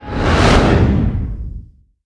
decloak.wav